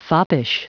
Prononciation du mot foppish en anglais (fichier audio)